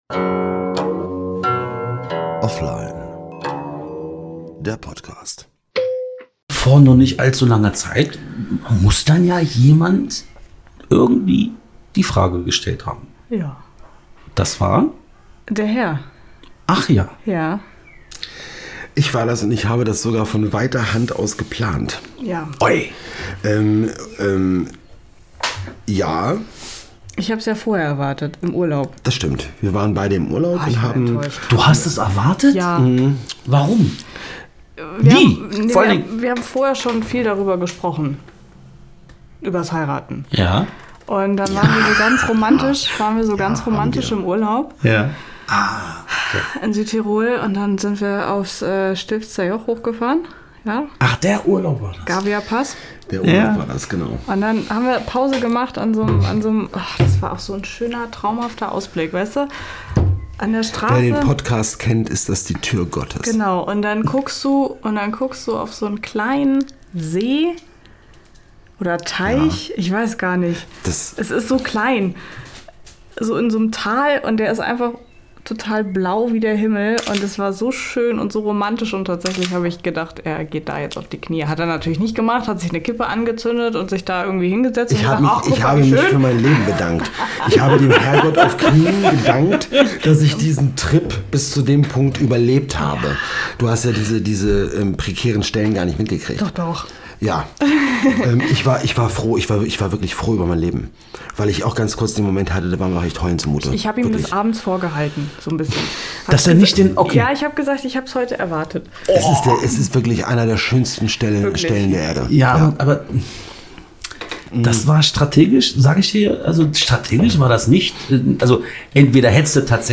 WEDDING-EDITION 2/4 Der 2. Tag in folge mit dem spannenden Doppelinterview.